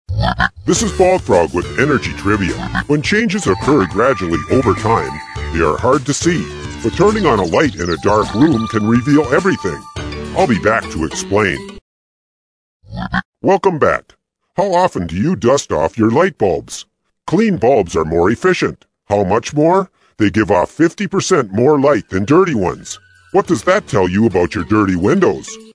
Bog Frog® Energy Trivia™ tips are fun, bite-size, radio features on energy conservation.
Bog Frog's voice is distinctive and memorable, while his messages remain positive and practical, in a memorable trivia format.